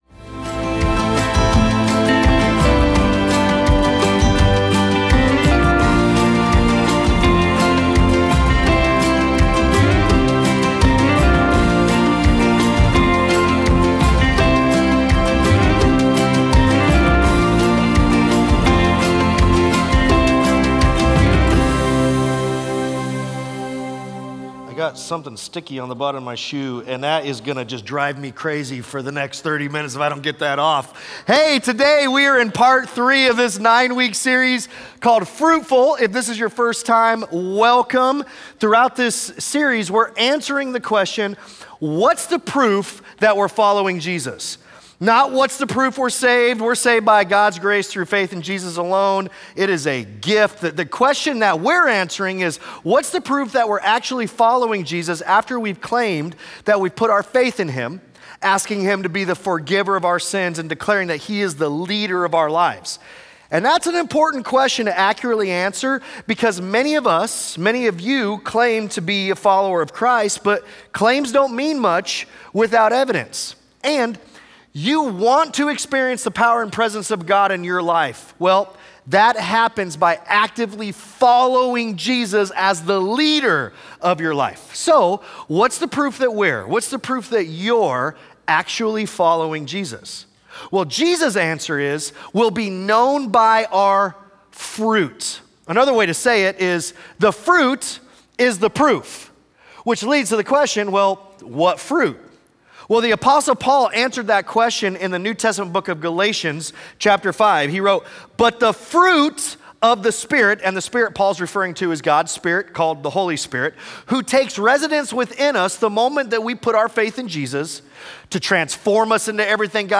Sunday Sermons FruitFULL, Week 3: "Peace" Feb 08 2026 | 00:37:42 1x 00:00 / 00:37:42 Subscribe Share Apple Podcasts Spotify Overcast RSS Feed Share Link Embed